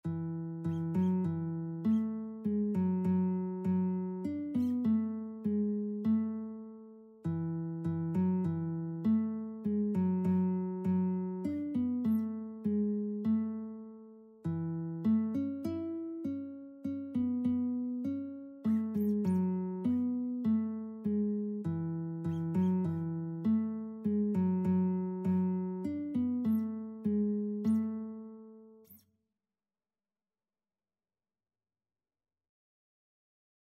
Christian
3/4 (View more 3/4 Music)
Classical (View more Classical Lead Sheets Music)